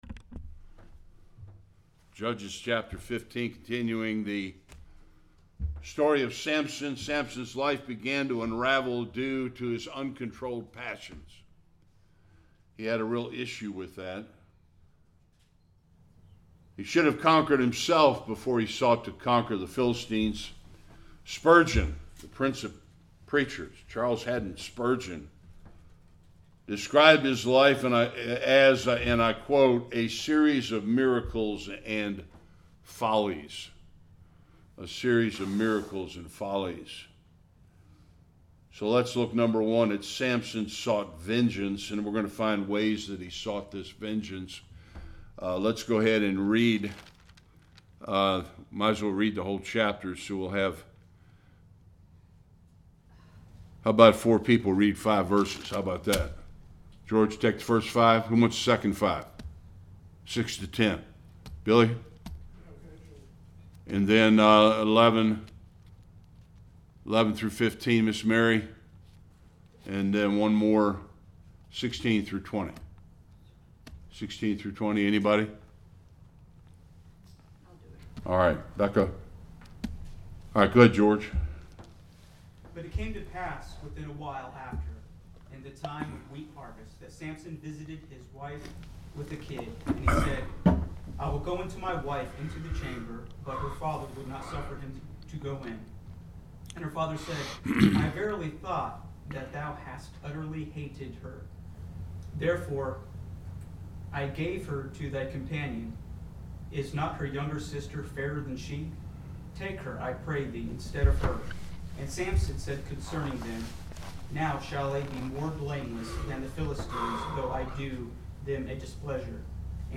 1-20 Service Type: Sunday School Samson exacts revenge twice against the Philistines in Chapter 15.